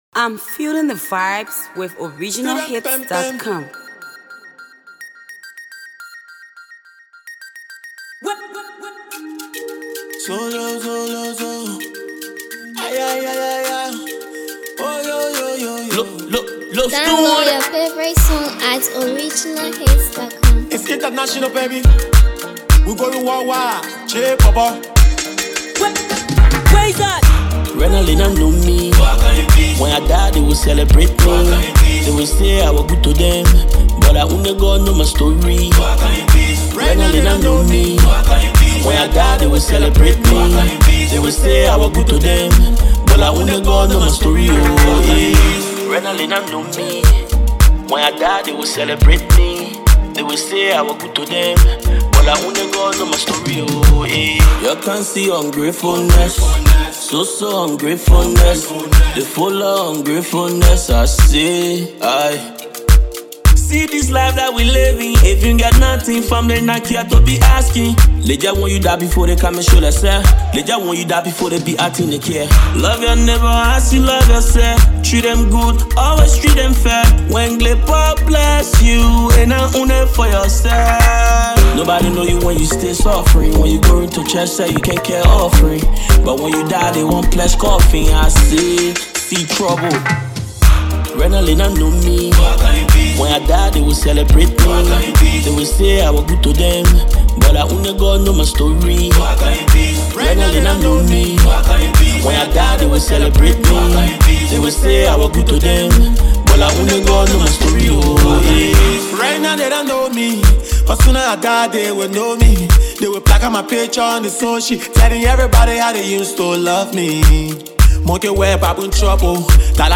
Don’t miss out on this versatile rapper’s latest hit.